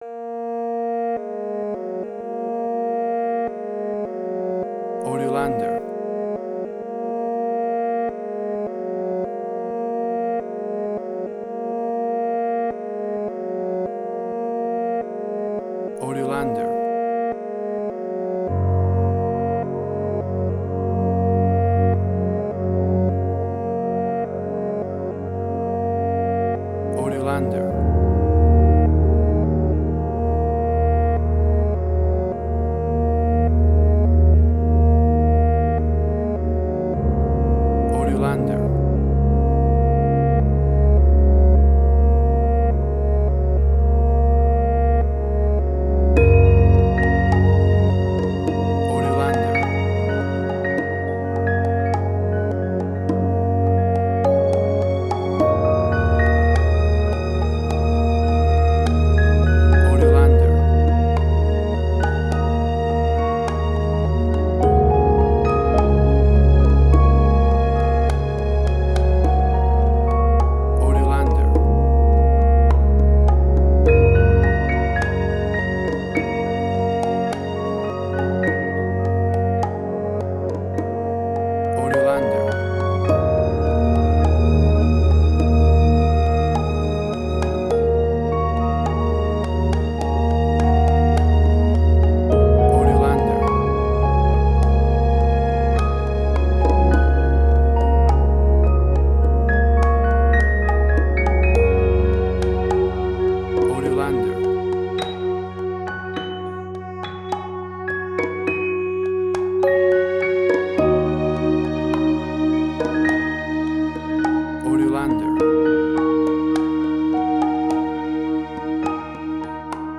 New Age
Tempo (BPM): 52